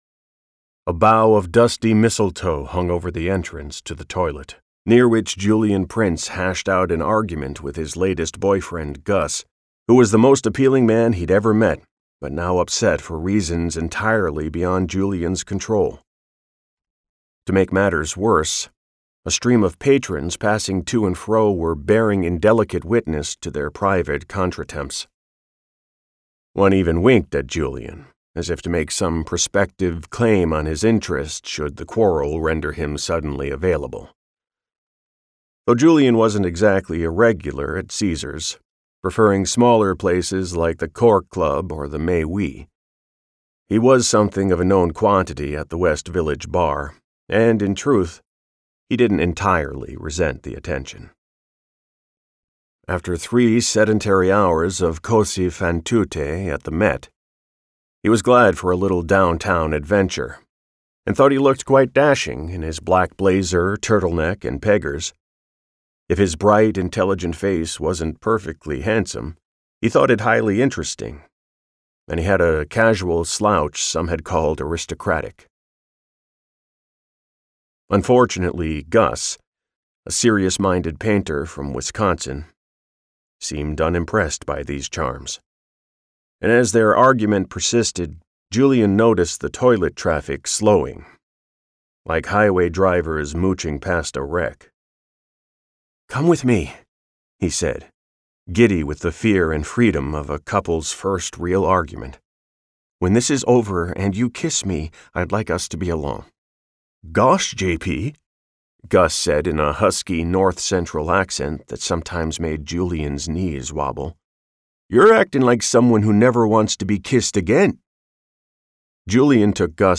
Disorderly Men - Vibrance Press Audiobooks - Vibrance Press Audiobooks